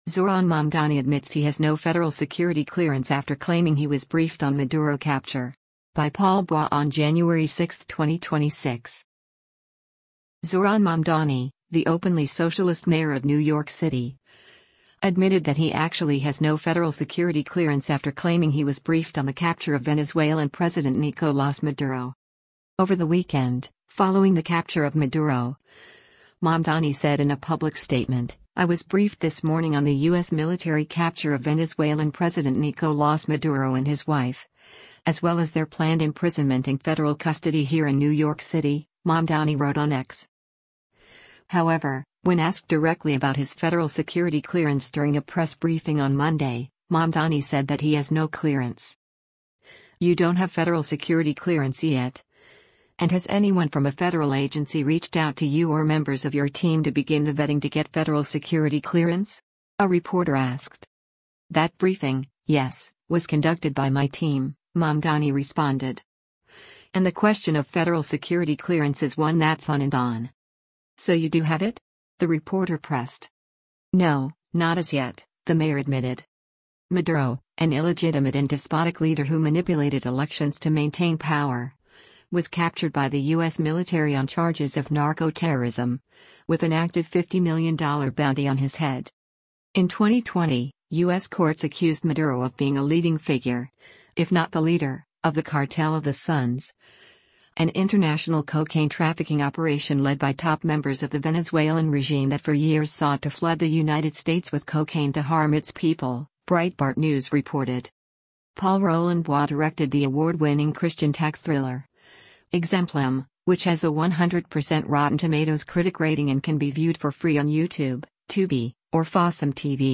However, when asked directly about his federal security clearance during a press briefing on Monday, Mamdani said that he has no clearance.